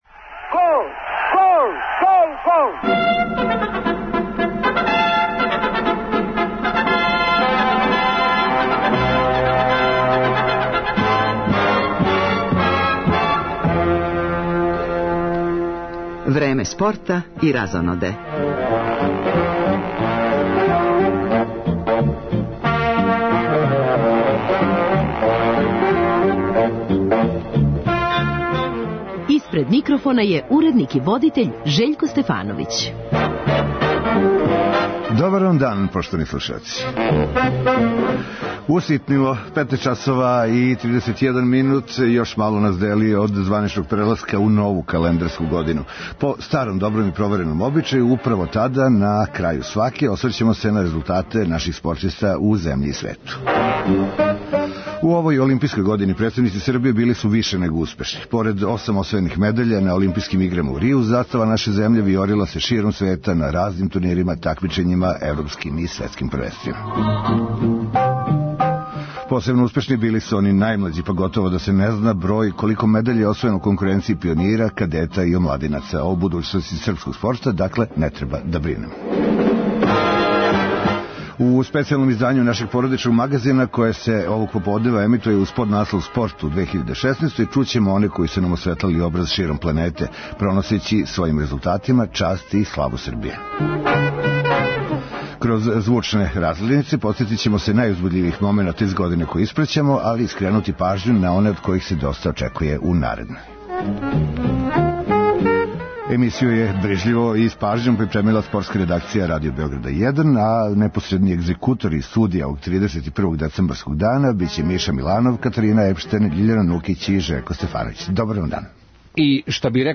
У специјалном издању нашег породичног магазина, које се емитује уз поднаслов `Спорт у 2016`, чућемо оне који су нам осветлали образ широм планете, проносећи својим резултатима част и славу Србије. Кроз `звучне разгледнице`, подсетићемо се најузбудљивих момената из године које испраћамо, али и скренути пажњу на оне од којих се доста очекује у наредној.